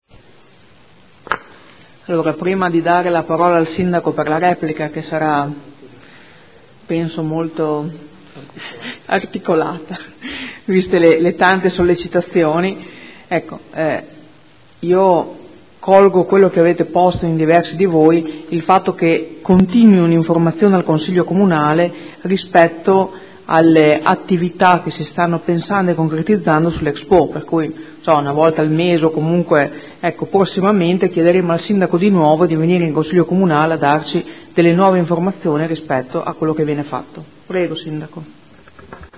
Seduta del 09/02/2015. Dibattito sugli ordini del giorno/mozioni inerenti l'expo 2015